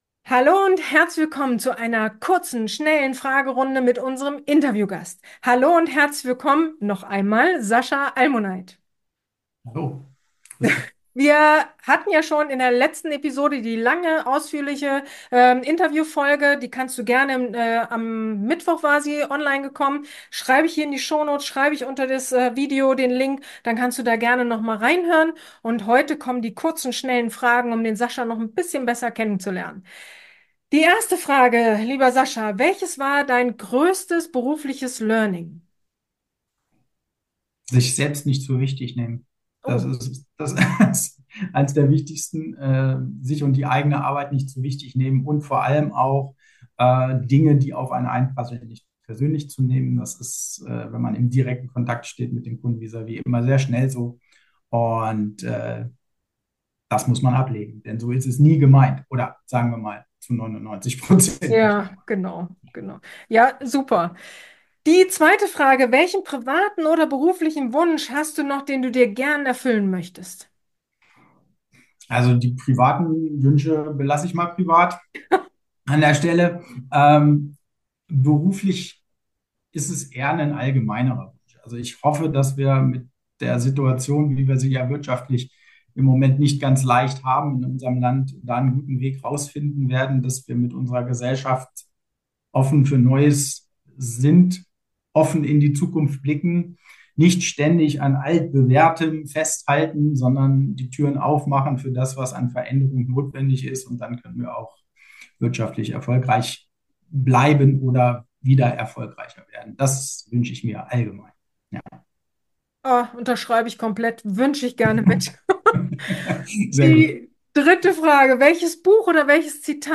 In dieser Freitags-Episode erwartet Dich eine schnelle Fragerunde